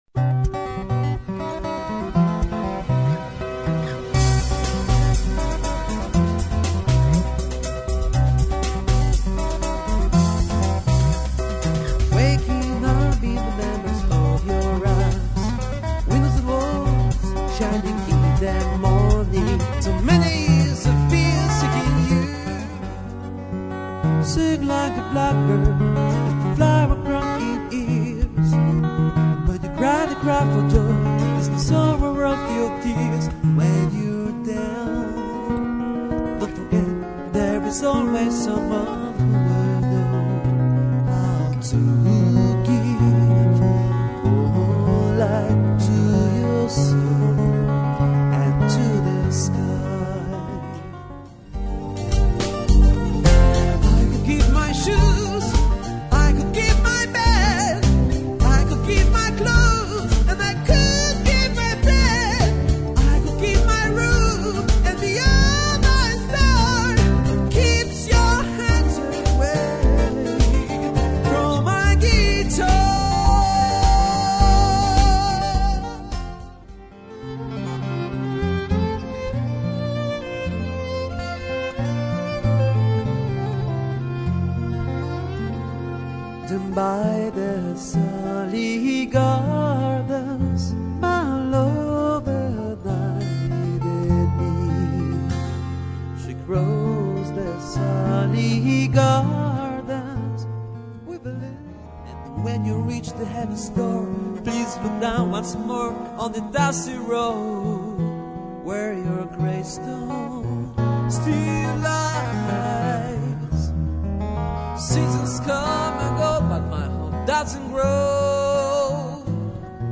- Gitarre und Gesang, Jazz, Pop-Rock-Songs der 50er Jahre bis aktuelle Hits der Neuzeit
• Medley eingene Stücke mit Gesang
medley-my-own-with-vocal.mp3